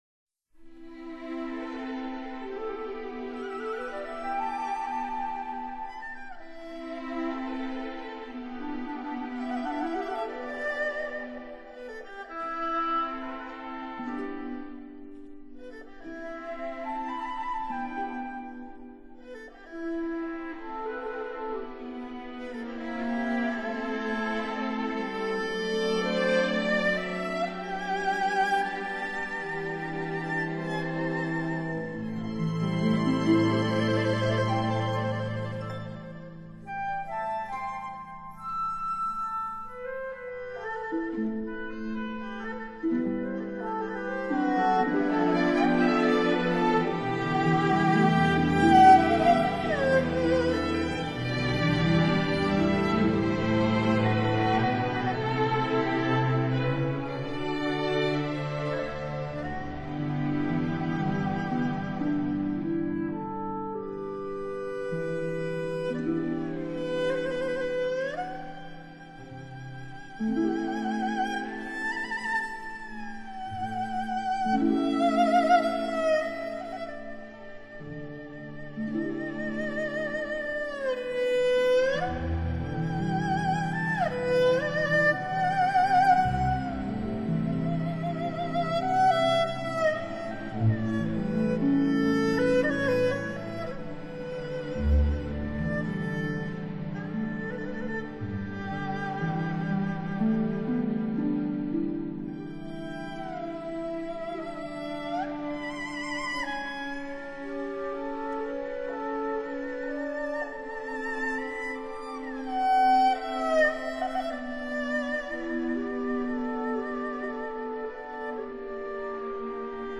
扬琴
钢琴